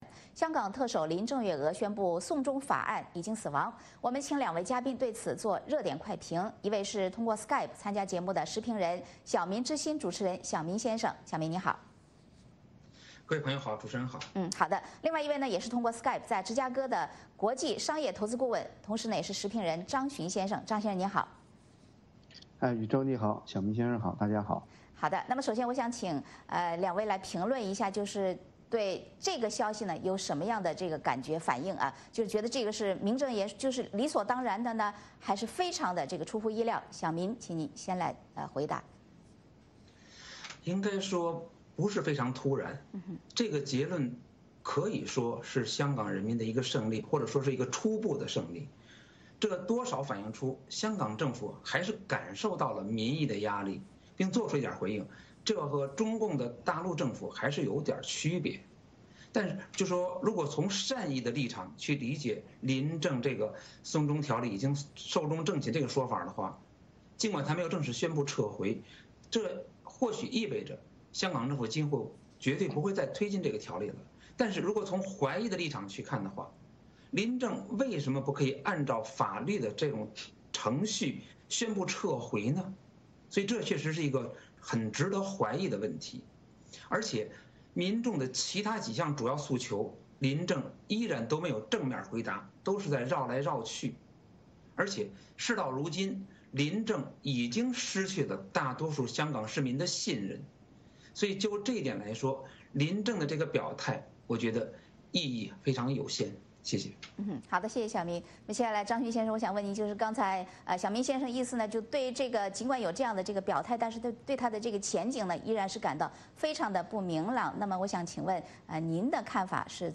时事大家谈是一个自由论坛。